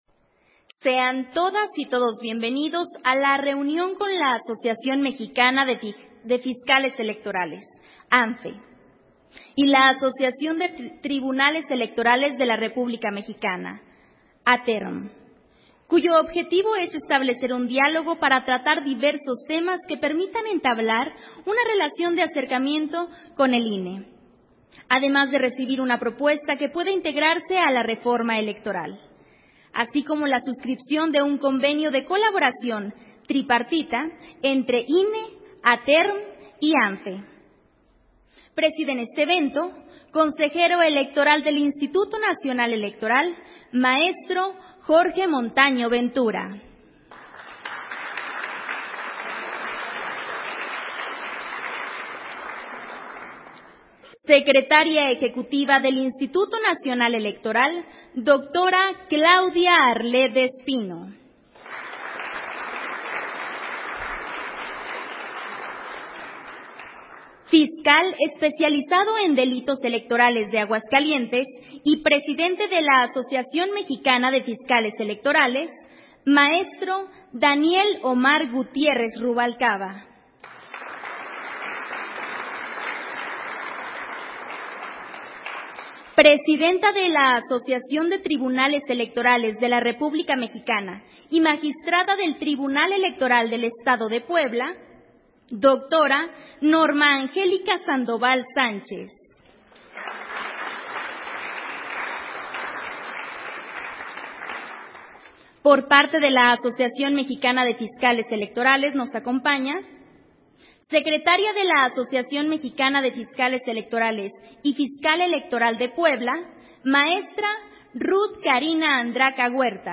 Audio de la reunión INE-ATERM-AMFE, para la recepción de sus propuestas para ser entregadas a la Comisión Presidencial de la Reforma Electoral y al Poder Legislativo